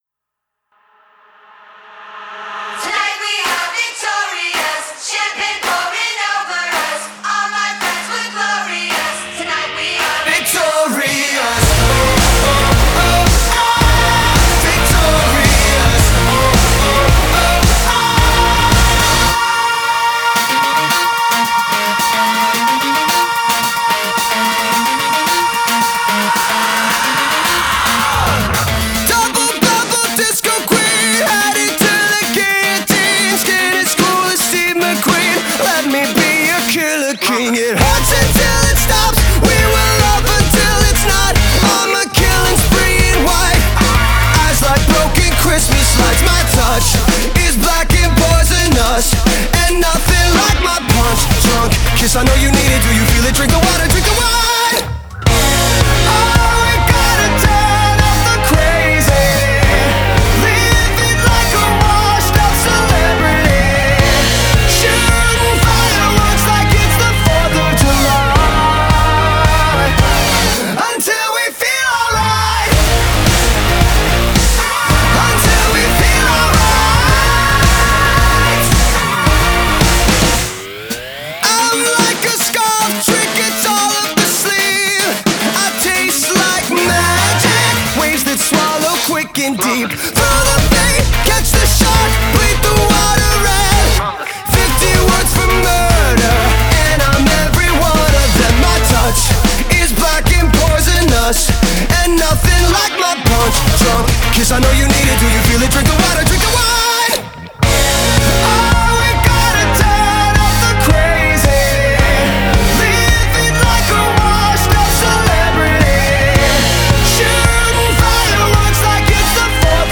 Genre: Alternative Rock, Pop Rock